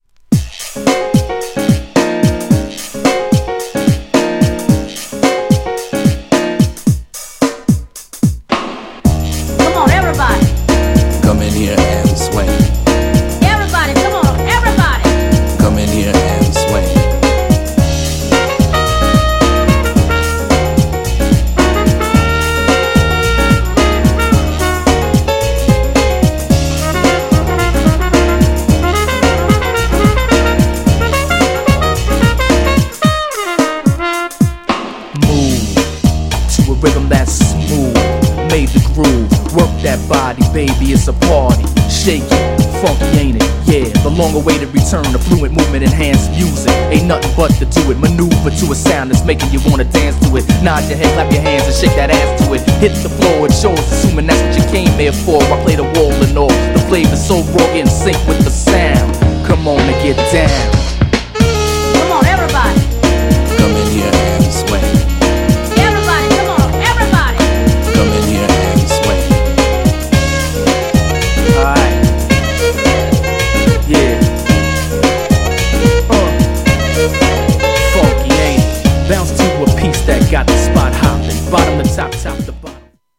GENRE R&B
BPM 106〜110BPM